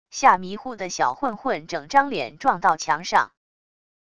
吓迷糊的小混混整张脸撞到墙上wav音频